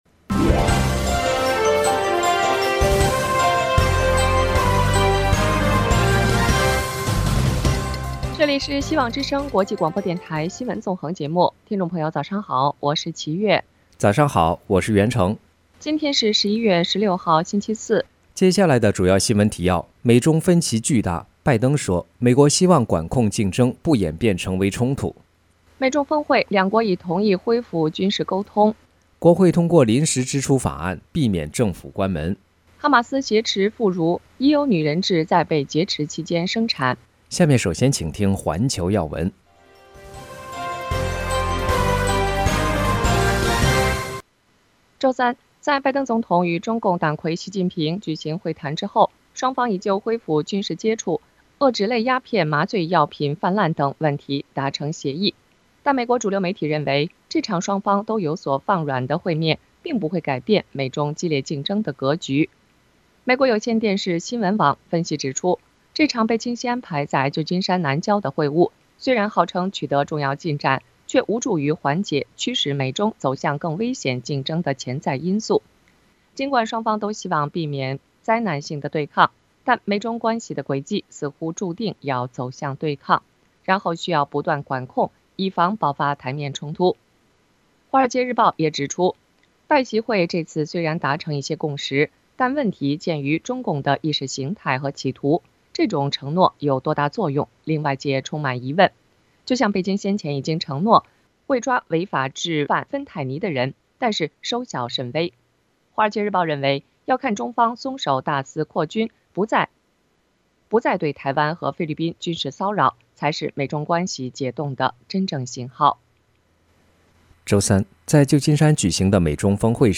希望之聲 - FM96.1 灣區台